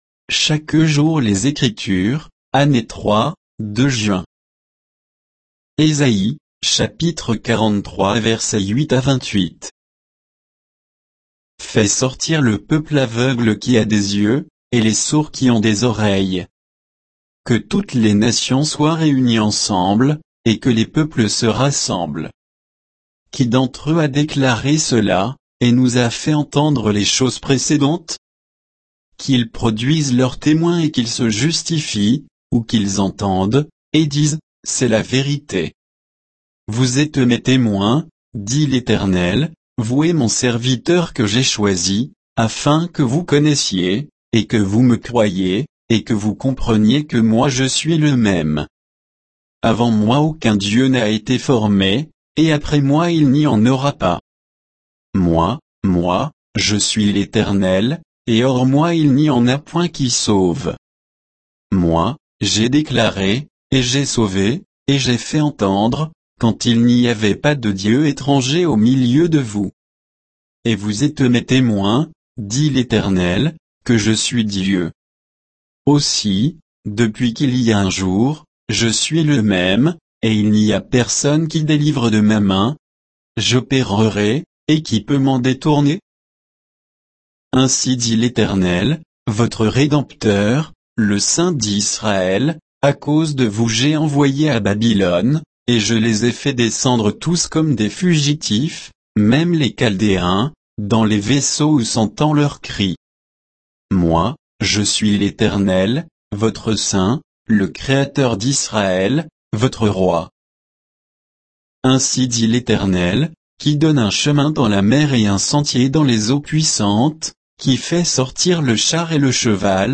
Méditation quoditienne de Chaque jour les Écritures sur Ésaïe 43, 8 à 28